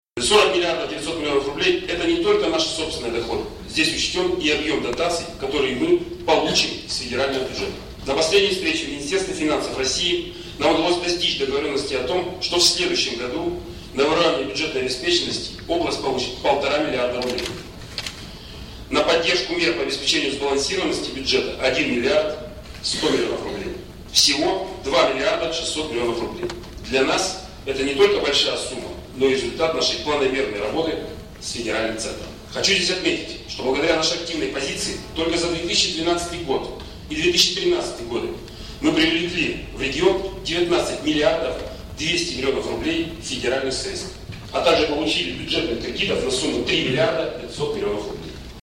С бюджетным посланием глава региона выступил на очередной 22-й сессии Законодательного Собрания области.
Олег Кувшинников рассказывает о бюджете Вологодской области